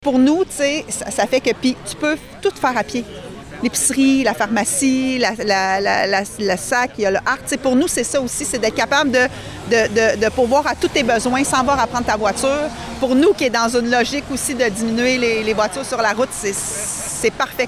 Pour la mairesse de Nicolet, Geneviève Dubois, l’emplacement du domaine est idéal.